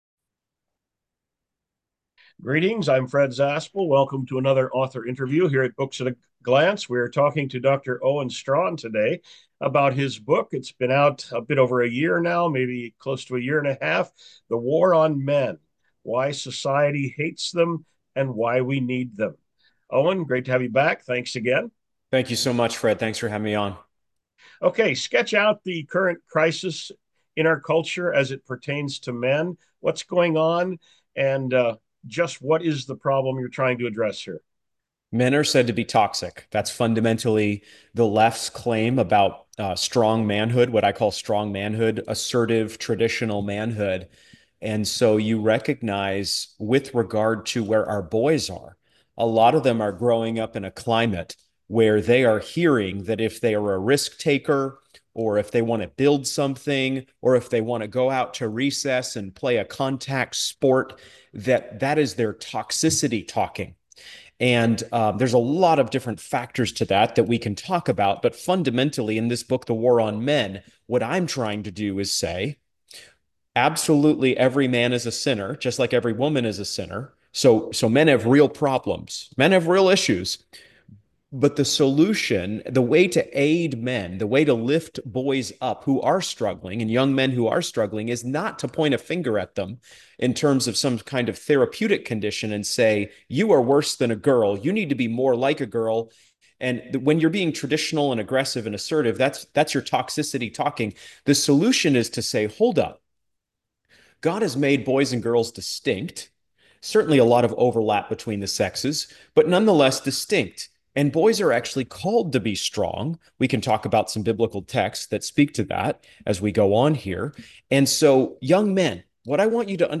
An Author Interview from Books At a Glance
Sample Audio Interview: